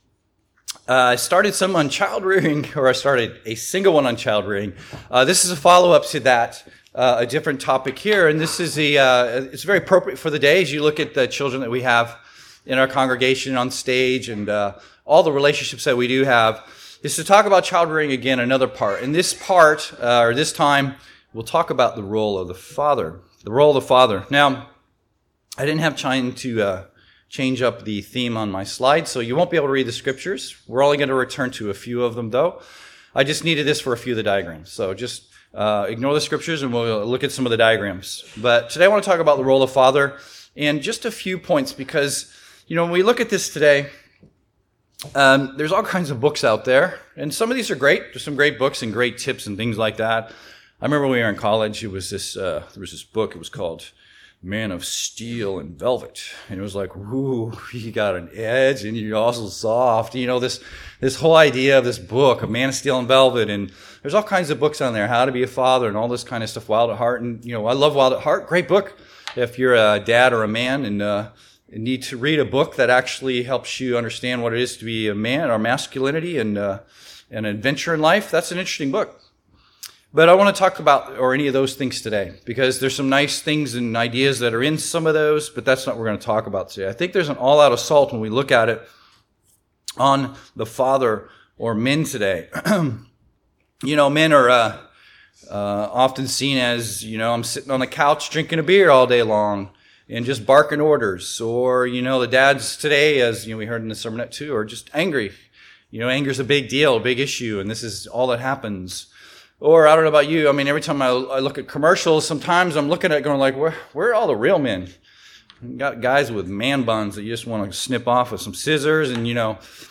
Given in Seattle, WA